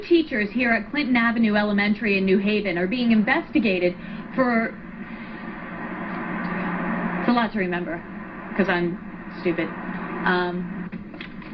Tags: News blooper news news anchor blooper bloopers news fail news fails broadcaster fail